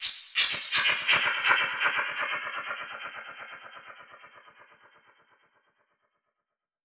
Index of /musicradar/dub-percussion-samples/125bpm
DPFX_PercHit_E_125-06.wav